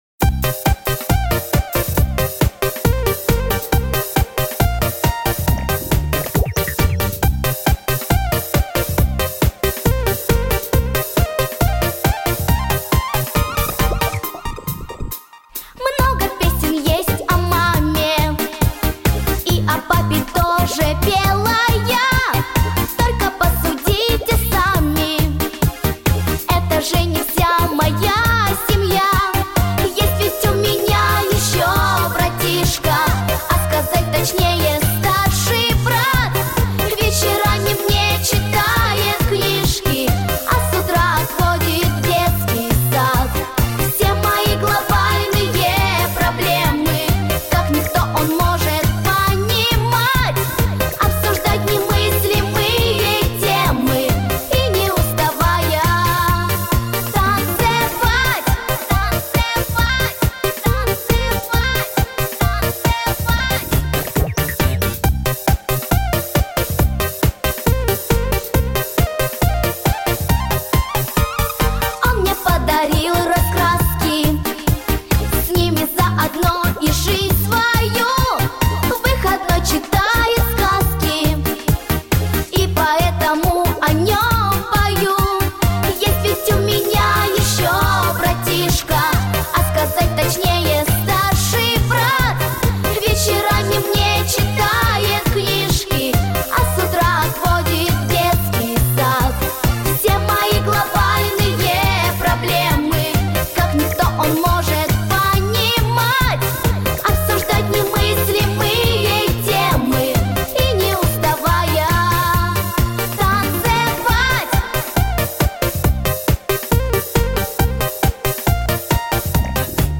• Категория: Детские песни
малышковые